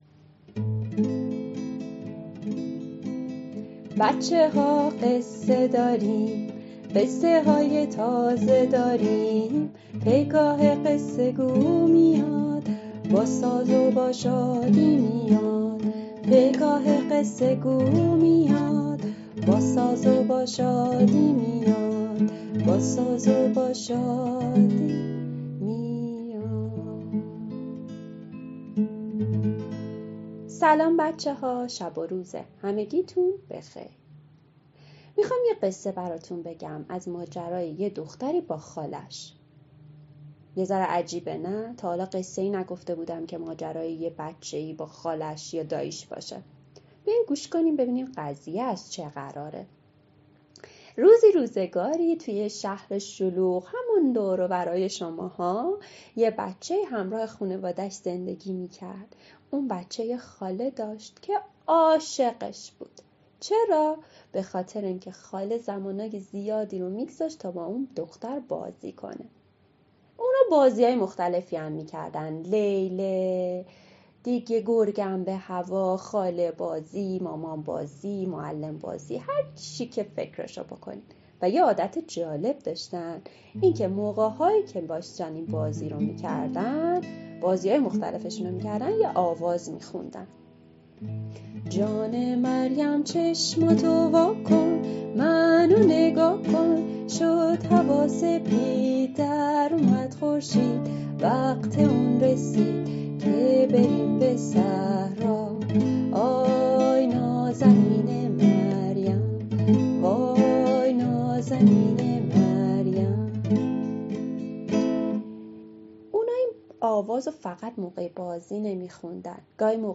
قصه صوتی کودکان دیدگاه شما 1,208 بازدید